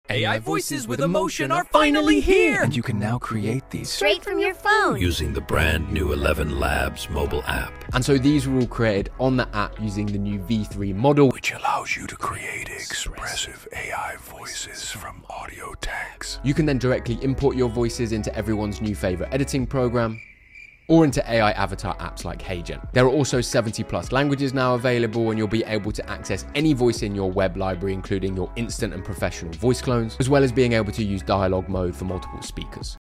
Ad | ElevenLabs Mobile App. Generative realistic AI voices using the new v3 model.